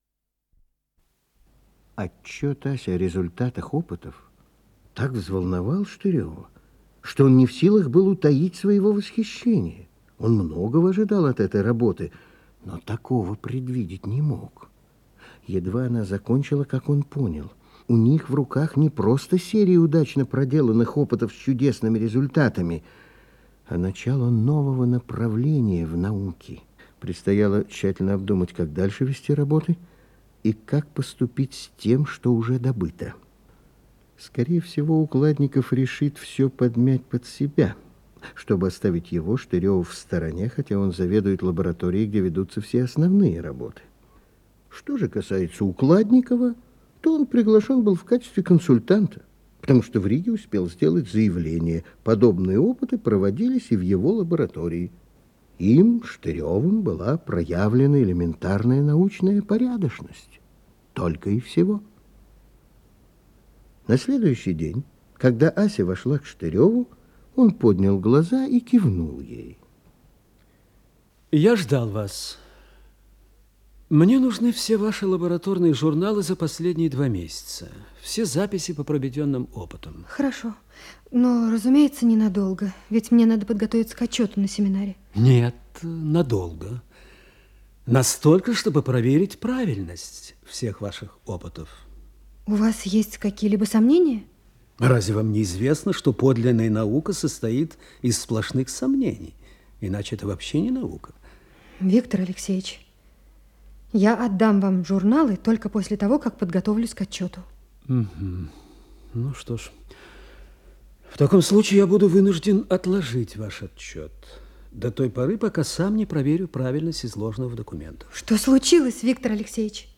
Р/п по повести "Эффект положения"